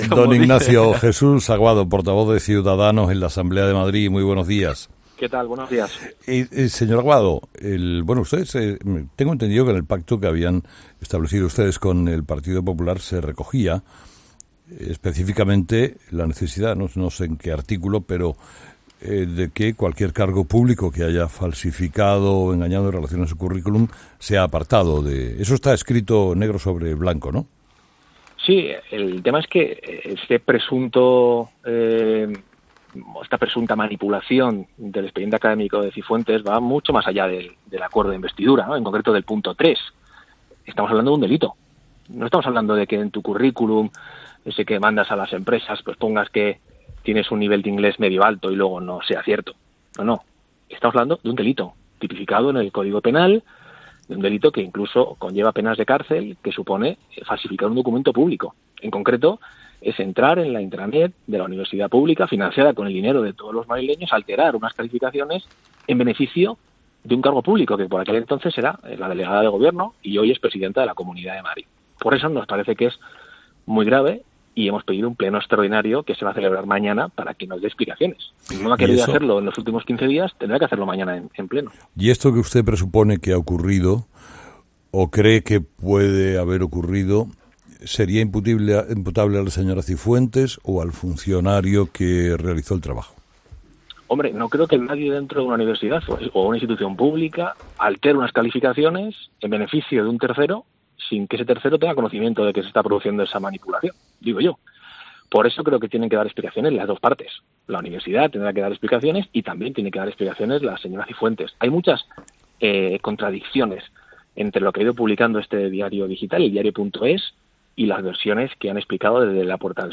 Entrevista a Ignacio Aguado, portavoz de CS en la Asamblea de Madrid